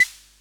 Claves.wav